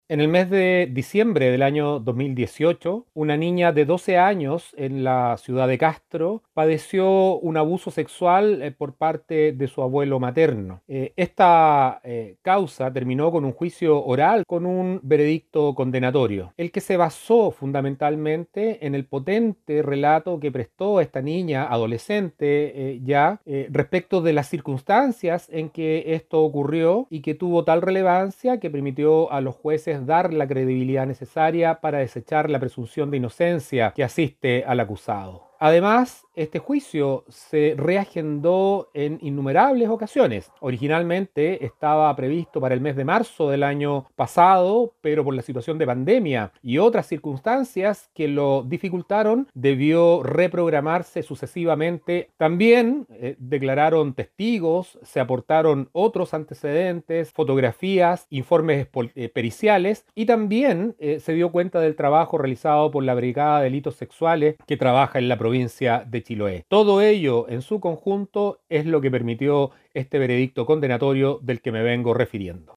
Así lo informó el Fiscal Enrique Canales: